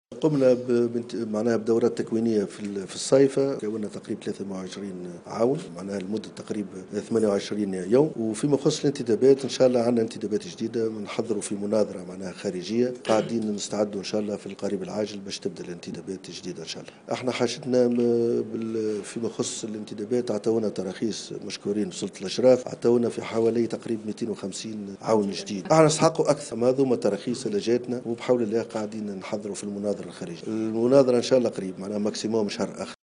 خلال ندوة صحفية